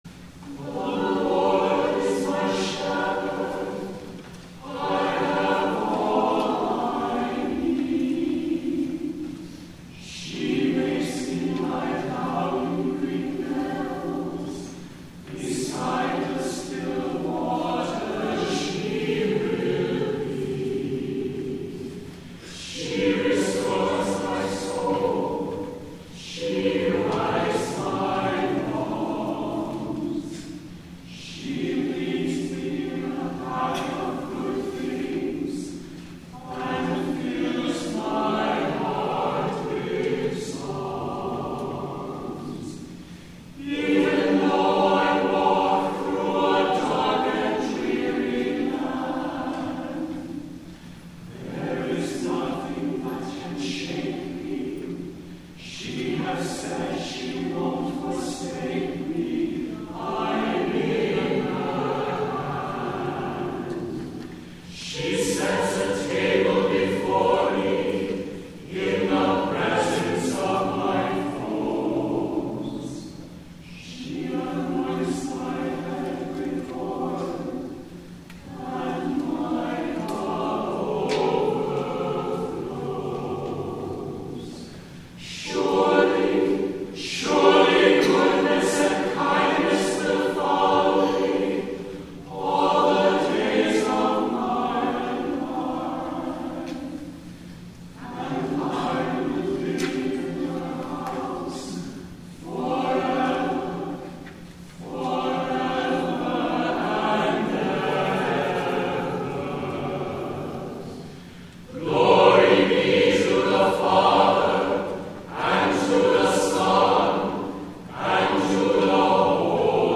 FOURTH SUNDAY OF LENT
THE PSALTER LESSON